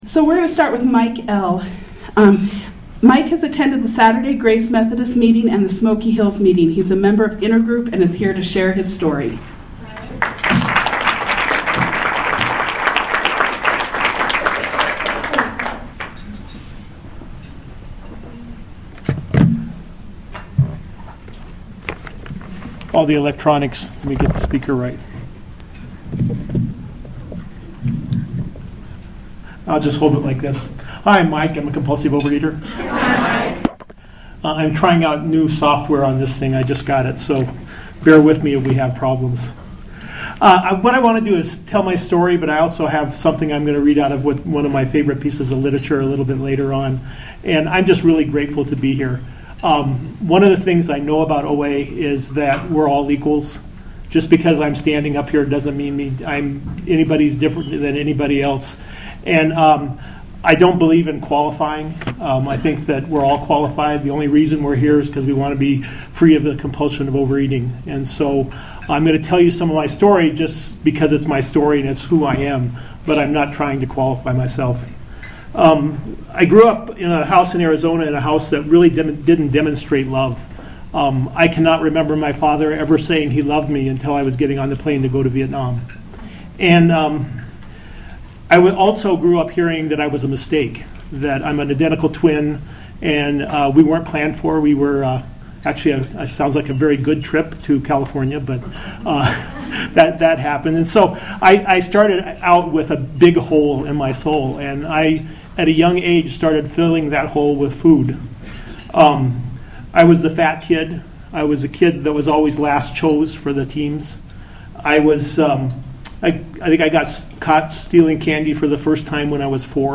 shared his story of recovery from compulsive overeating at OA’s Ninth Annual New Years Day brunch in Lakewood.